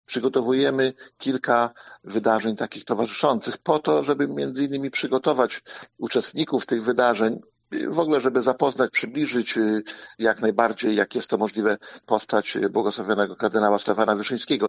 O przygotowaniach mówi Starosta Łomżyński, Lech Szabłowski: